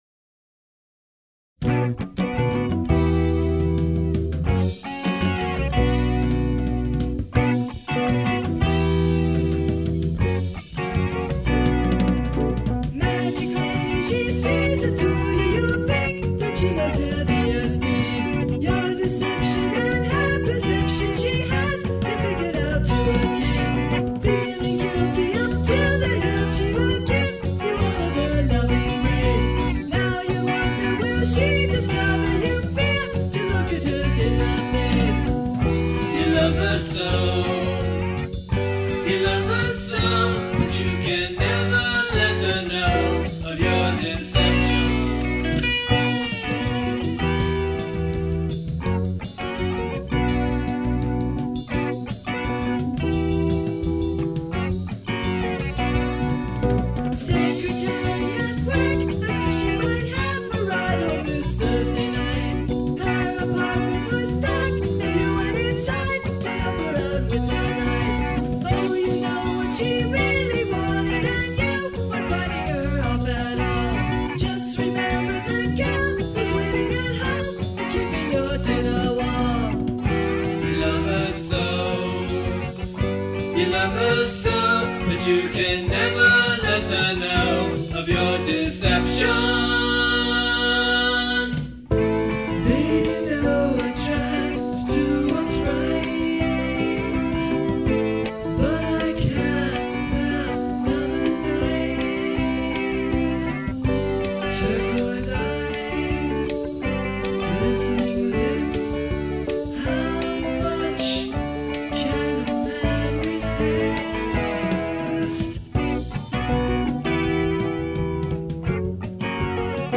During that weekend the group recorded eleven songs on the Tascam 22-4, 4-track, reel-to-reel, tape recorder.